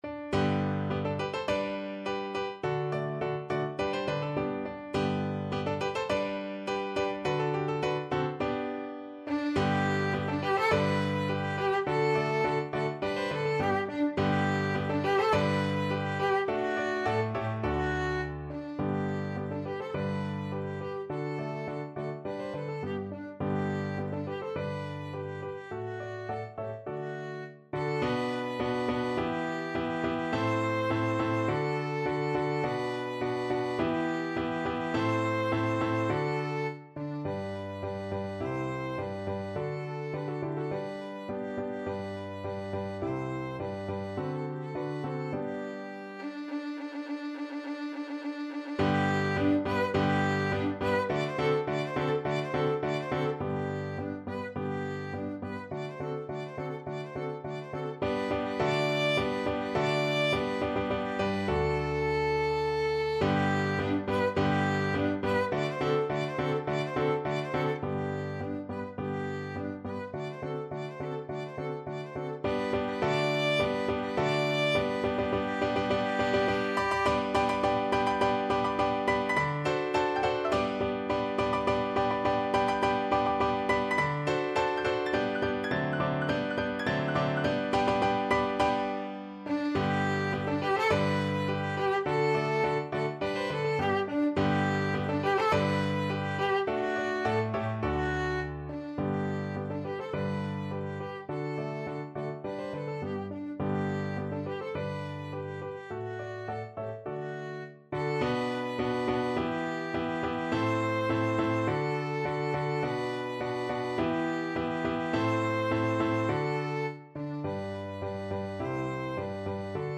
2/4 (View more 2/4 Music)
~ = 100 Molto vivace =104
Classical (View more Classical Violin Music)